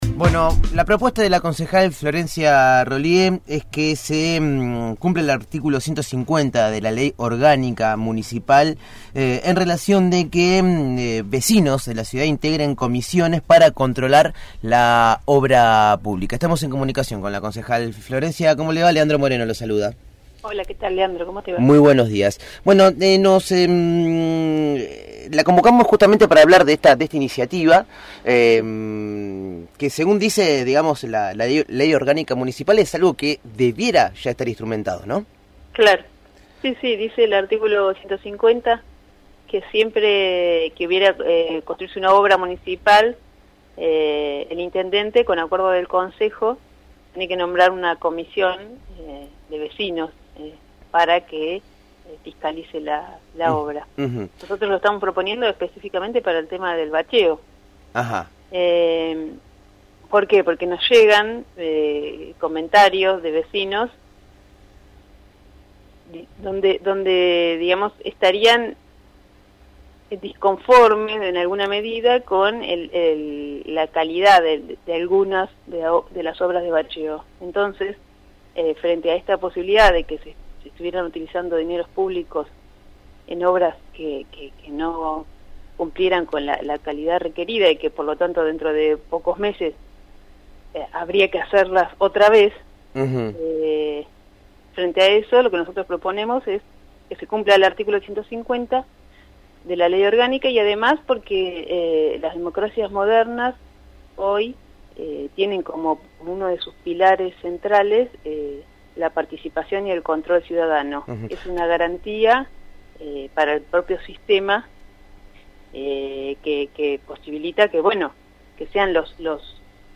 Florencia Rollié, concejal platense por Cambiemos, dialogó con el equipo de «El hormiguero» sobre pedido que realizó mediante una resolución para que se integren las Comisiones de Vecinos en el control de la obra pública, como dice el artículo 150 de la Ley Orgánica Municipal.